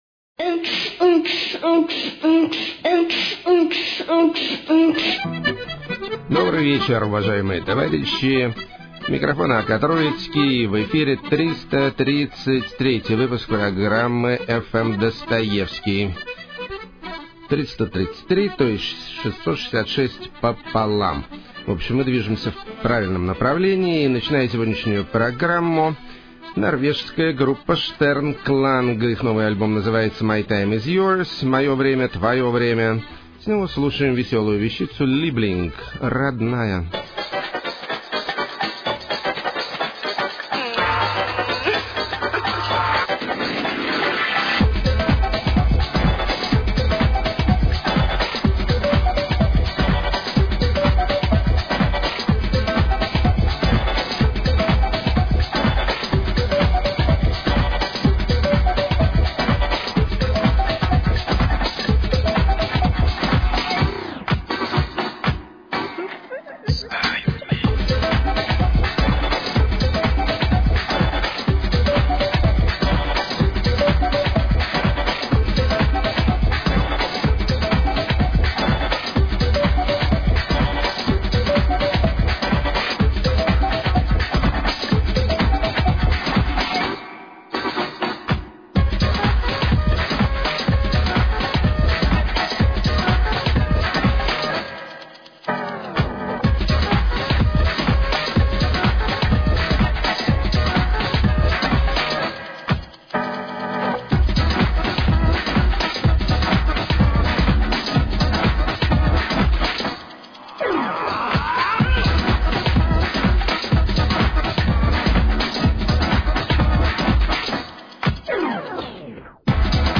Crystal Cold Nordic Electro
Humorous Lo-fi
Wallone Indie Kitsch
Juicy Habana-rap
Twisted Folk Gothic
Insane Nu-jazz
Brutal Use Of Musical Saw